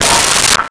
Metal Lid 02
Metal Lid 02.wav